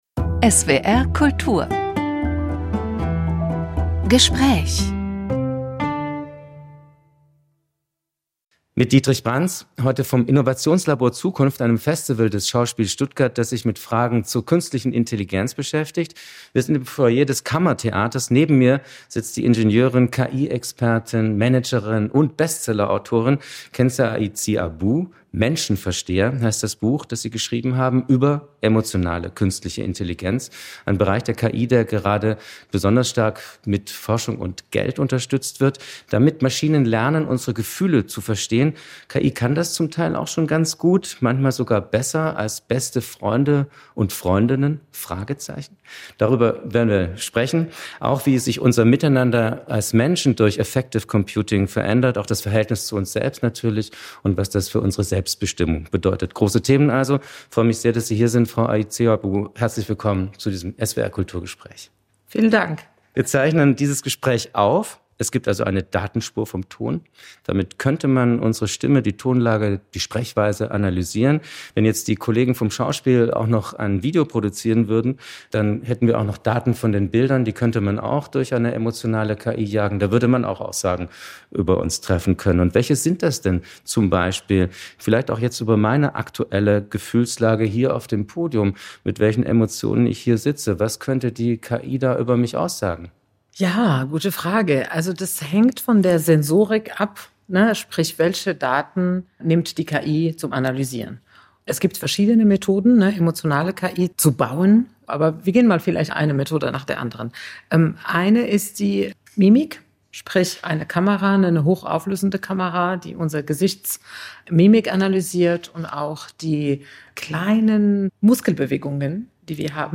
(Öffentliche Veranstaltung vom 31. Mai 2025 im Schauspiel Stuttgart)